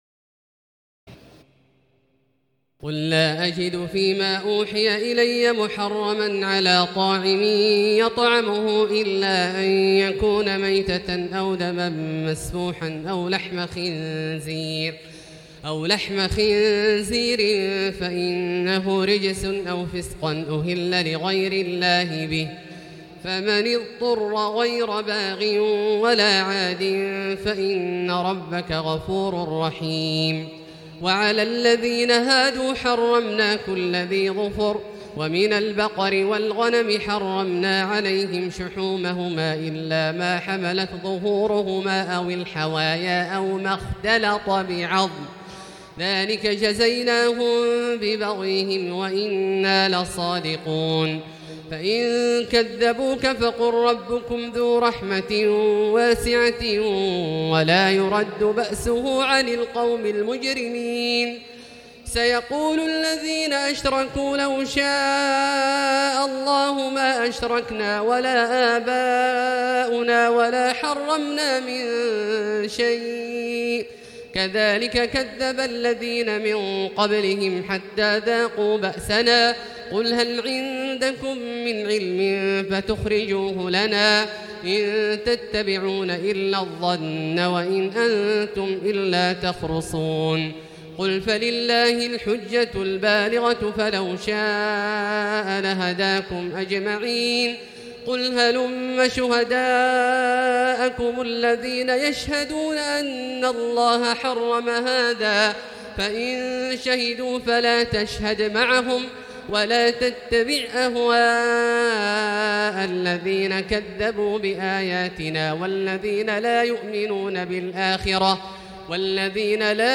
تراويح الليلة السابعة رمضان 1439هـ من سورتي الأنعام (145-165) و الأعراف (1-64) Taraweeh 7 st night Ramadan 1439H from Surah Al-An’aam and Al-A’raf > تراويح الحرم المكي عام 1439 🕋 > التراويح - تلاوات الحرمين